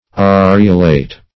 Areolate \A*re"o*late\, Areolated \A*re"o*la*ted\, a. [L.